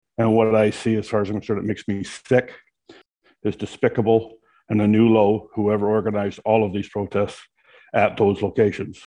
At Monday’s council meeting Councillor Al Dewitt said healthcare workers have had everyone’s backs through the pandemic and had bent over backwards to keep communities safe.